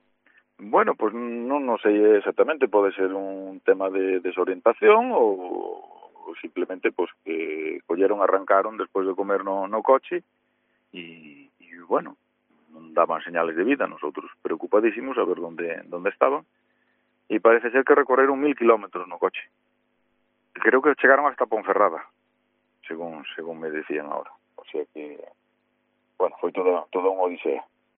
El alcalde de Valadouro explica que la pareja hizo 1.000 kilómetros